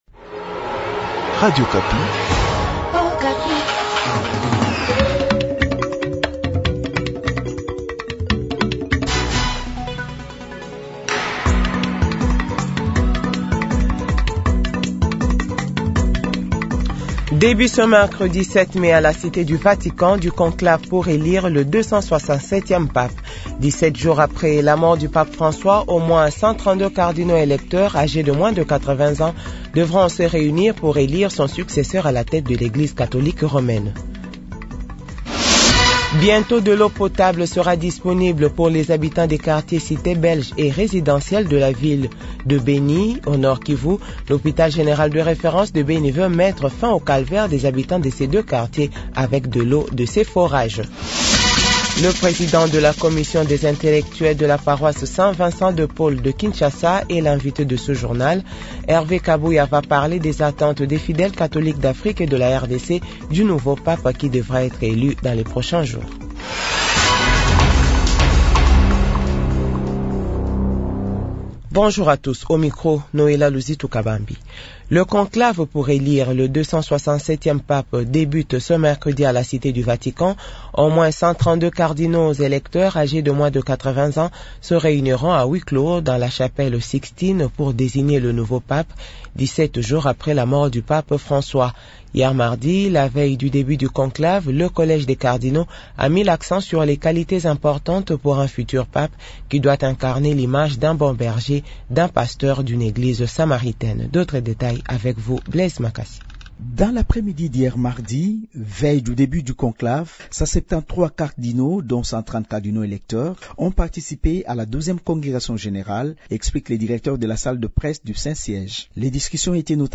Jounal 8h